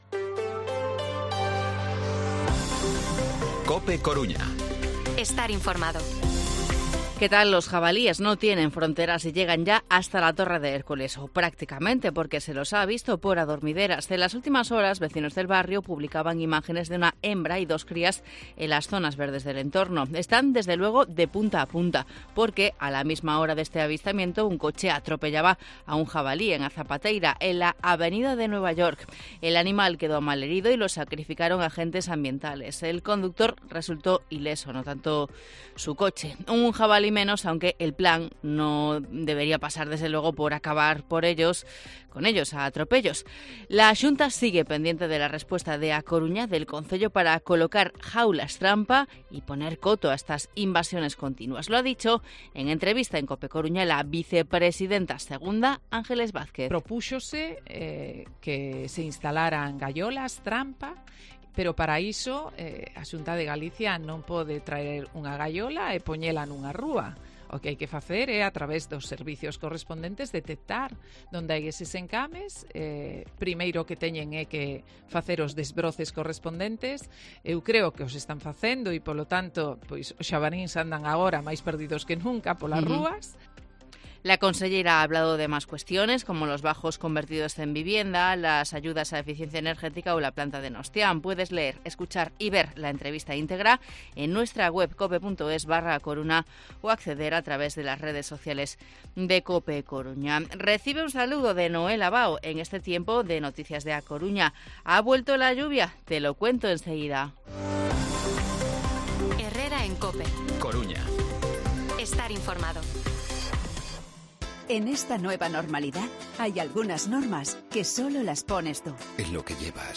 Informativo Herrera en COPE Coruña martes, 3 de octubre de 2023 8:24-8:29